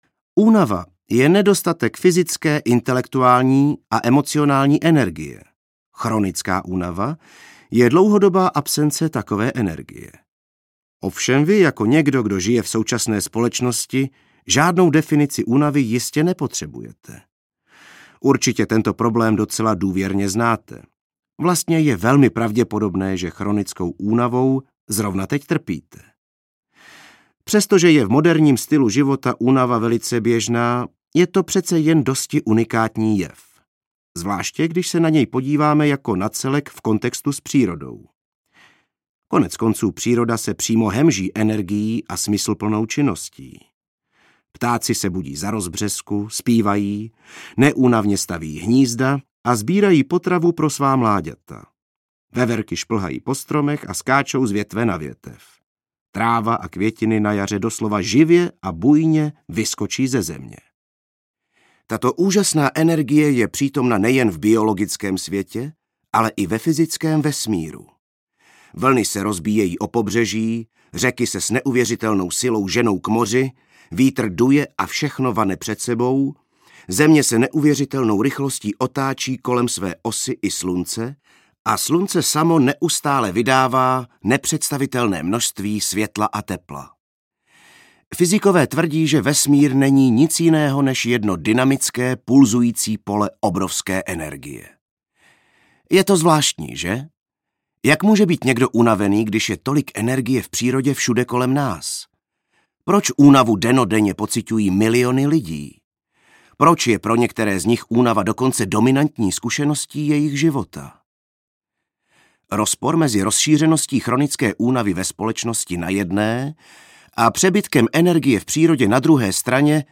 Ukázka z knihy
nespoutana-energie-audiokniha